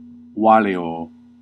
Ääntäminen
Ääntäminen Classical: IPA: /ˈwa.le.oː/ Haettu sana löytyi näillä lähdekielillä: latina Käännös 1. no hay inconveniente 2. estoy bien, gracias Taivutusmuodot Supiini valitūrum Luokat Keskiajan latina Kirkkolatina